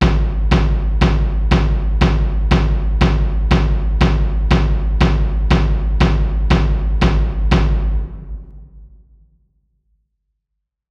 【アカペラ】ポッキーの日なのでボイスパーカッションの１問１答を作ってみた！